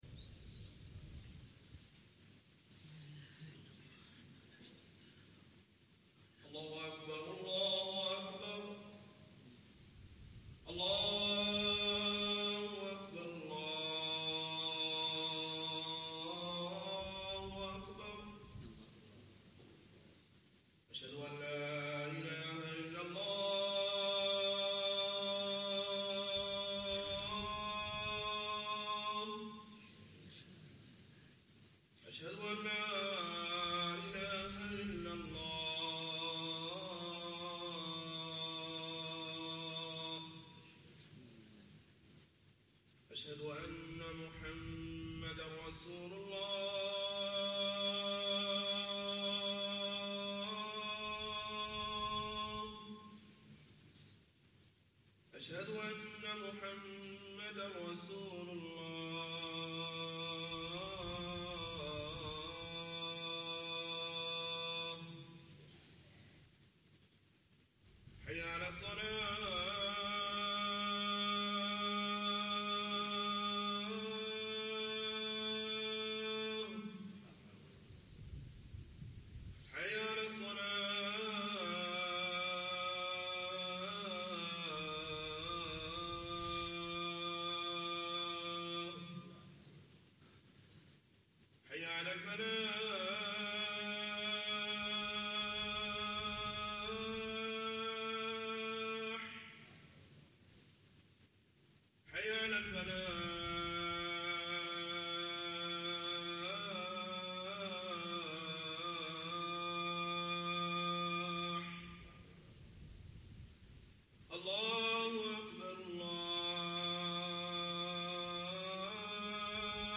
الخطبه
خطب الجمعة